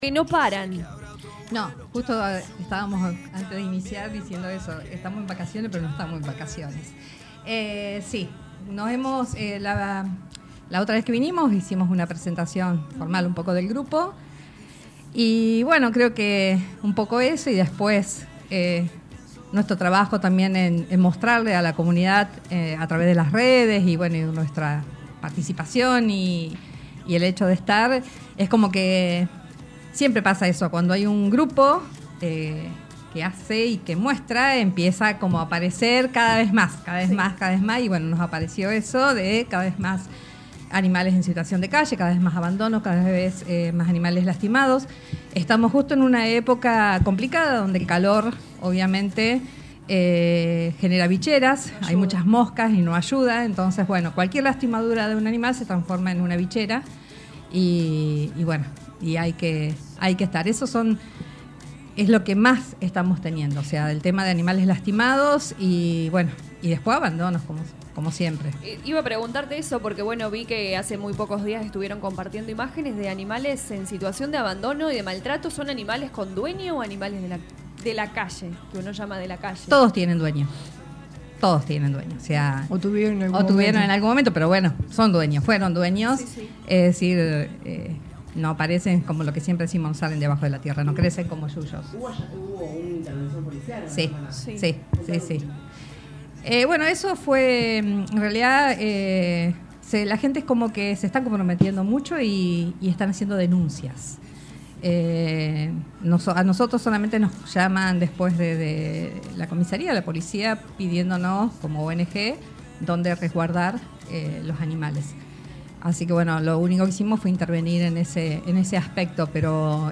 En diálogo con LA RADIO 102.9, contaron sobre la actualidad de la ONG y remarcaron que continúan por la protección de los animales rescatados del abandono y maltrato para su posterior adopción, garantizando un hogar seguro.